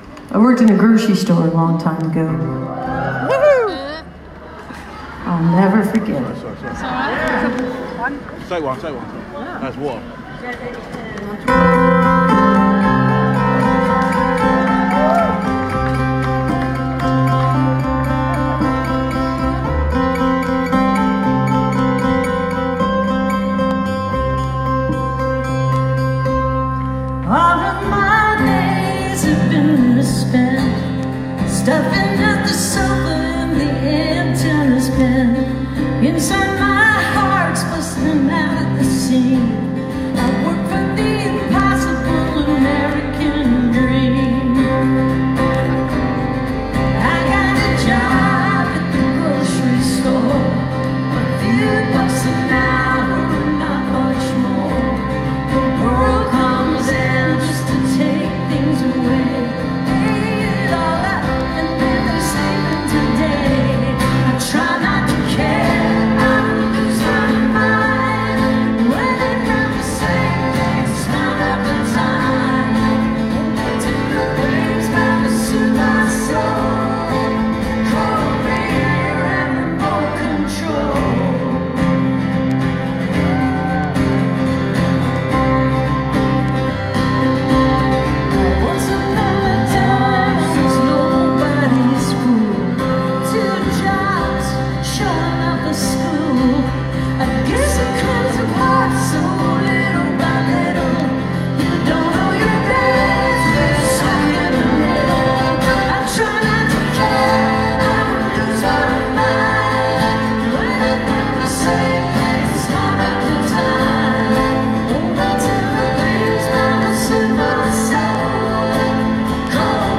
(captured from facebook live streams)